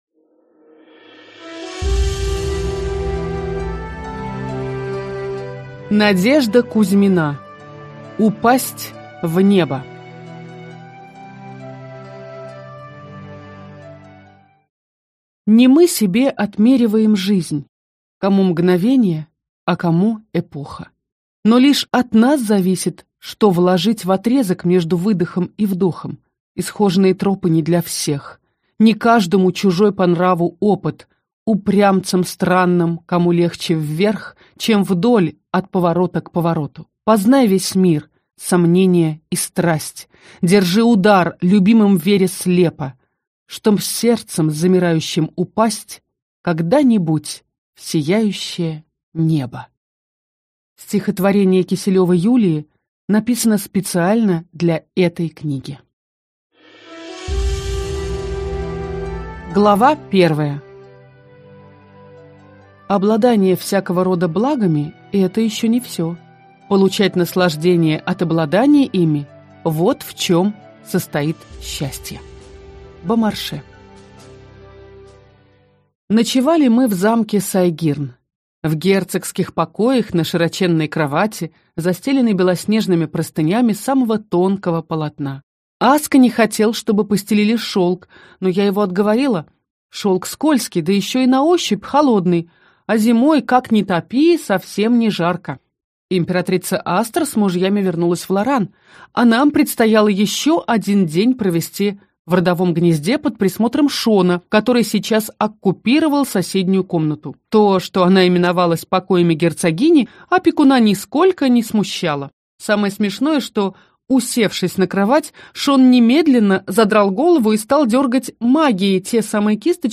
Аудиокнига Упасть в небо | Библиотека аудиокниг